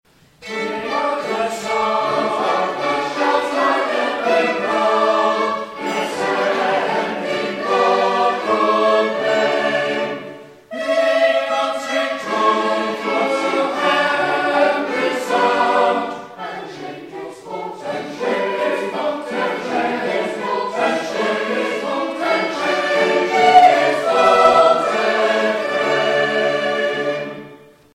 West Gallery Music in the Chilterns
This joyful music was sung in the West Galleries of English country churches in the 1700s.
We recreate this sound in the usual four voice parts: soprano, alto, tenor and bass and include instruments such as violins, clarinets and a serpent.